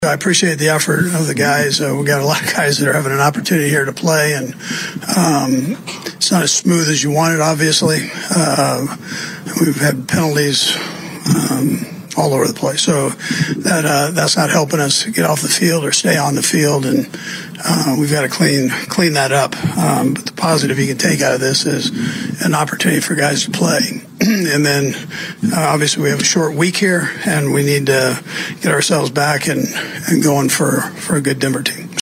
Coach Andy Reid talked about the players getting a chance to play with all the injuries the Chiefs have right now